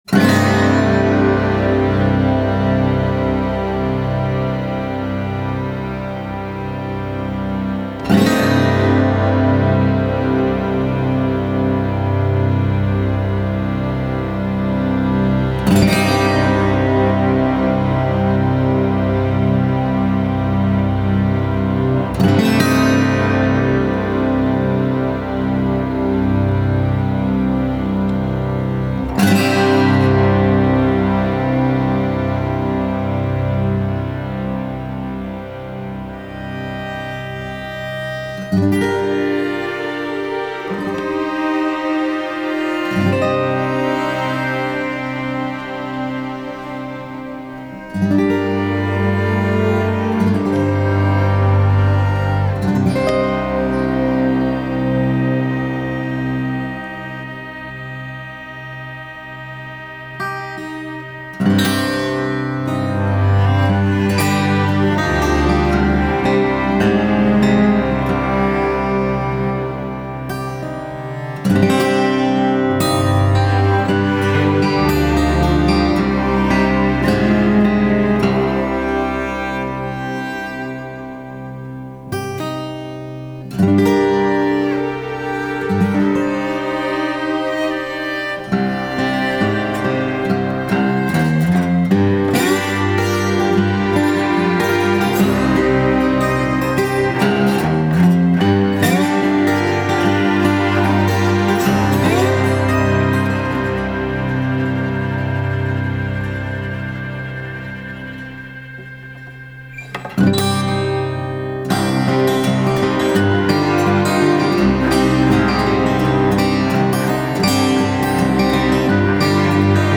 features a 10 piece string ensemble